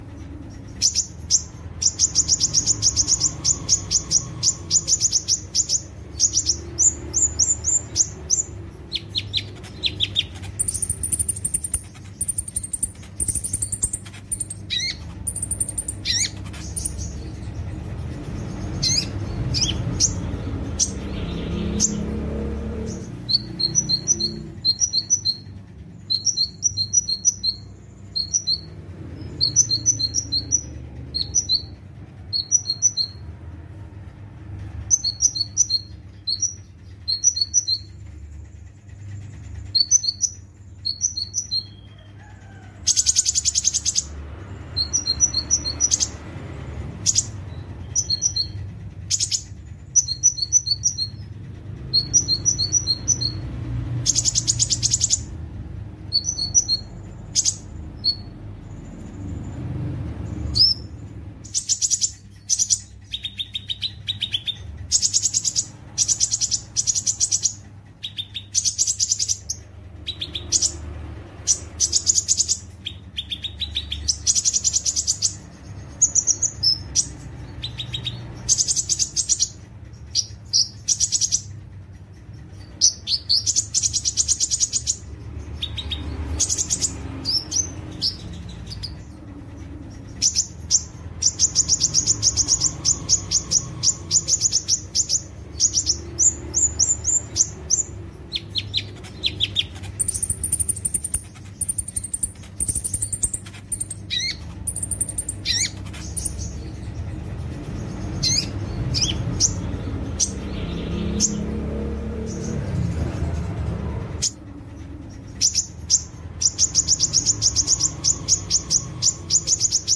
Suara Kolibri Ninja Betina Memanggil Jantan
Tag: suara burung betina suara burung kecil
suara-burung-kolibri-ninja-jernih-id-www_tiengdong_com-1.mp3